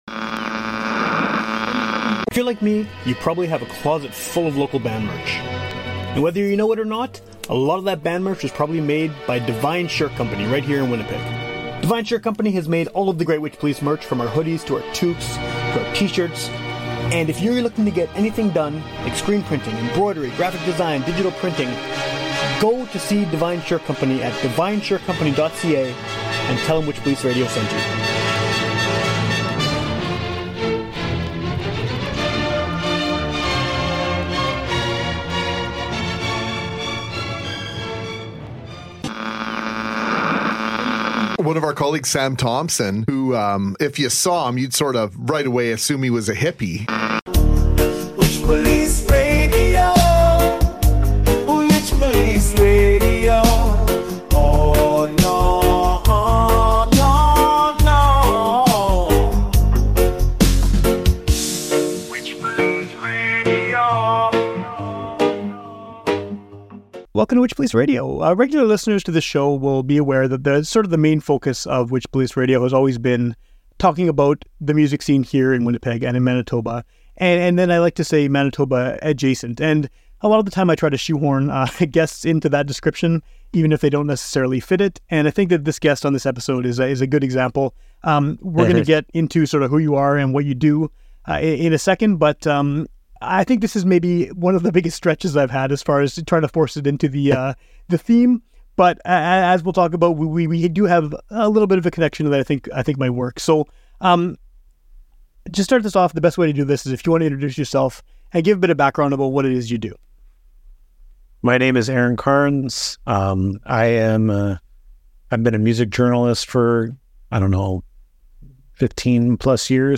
Check out our conversation, and thank you for occasionally indulging me when I make episodes that have little to no connection to Manitoba music.